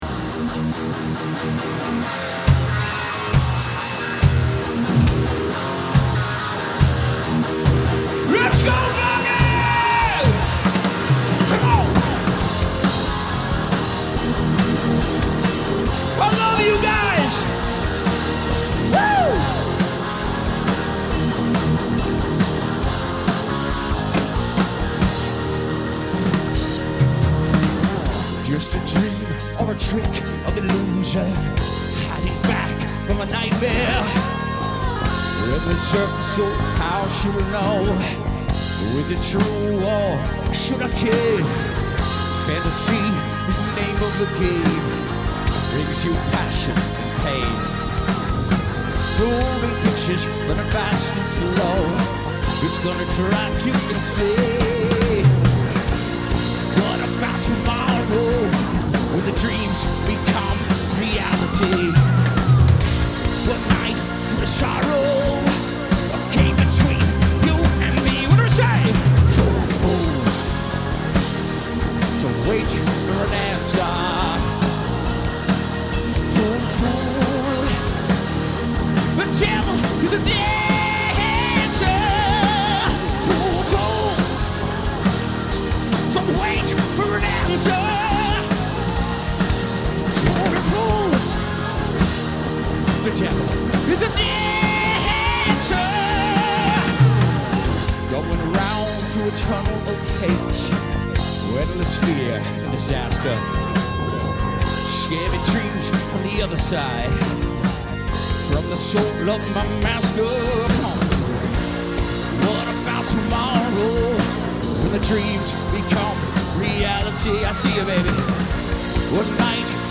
Wacken Open Air, Wacken, Germany [SBD]